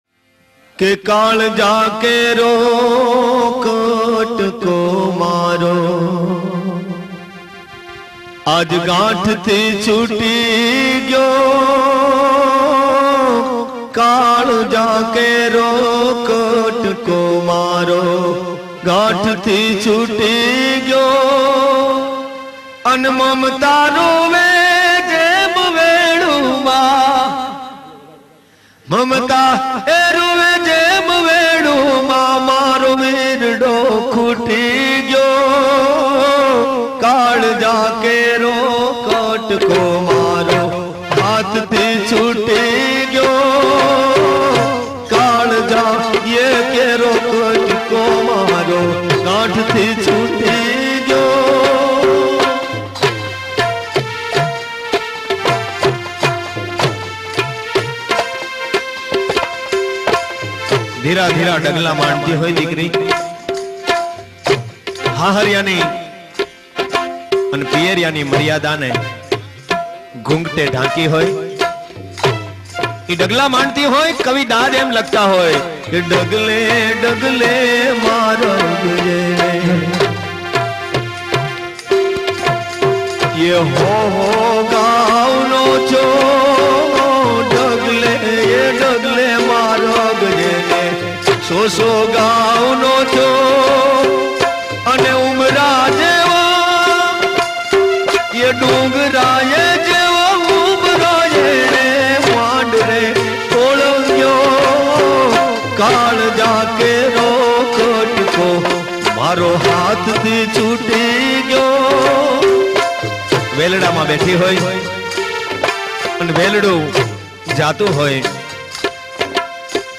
ગીત સંગીત લગ્ન ગીત (Lagna Geet)